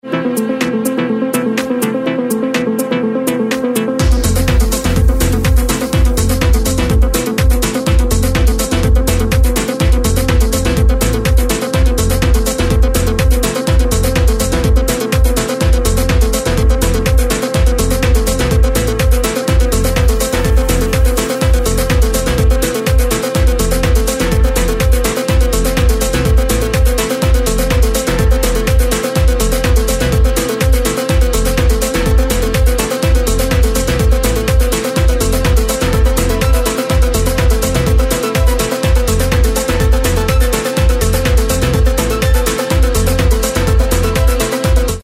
TECH HOUSE / TECHNO